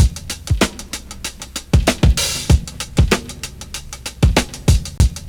BEAT 3 96 02.wav